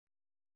♪ tołali kallu